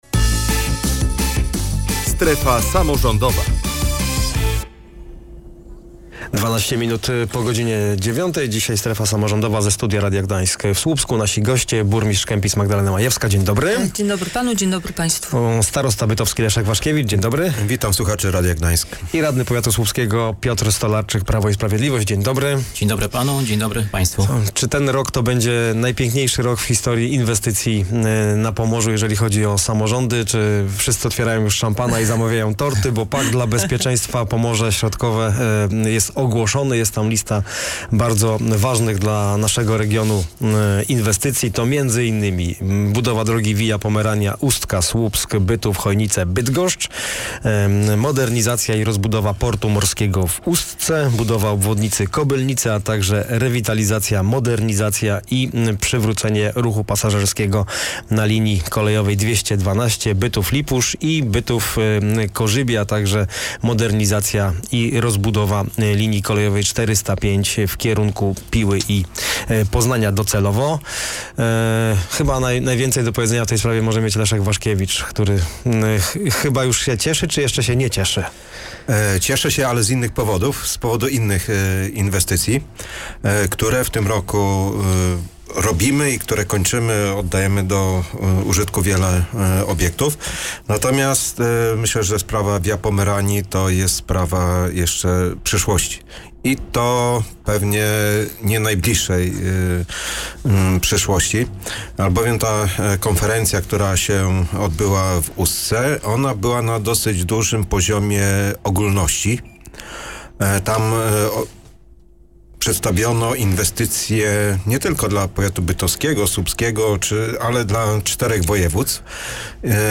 O trasie Via Pomerania rozmawiali w audycji „Strefa Samorządowa”: starosta bytowski Leszek Waszkiewicz, burmistrz Kępic Magdalena Majewska, a także radny powiatu słupskiego Piotr Stolarczyk.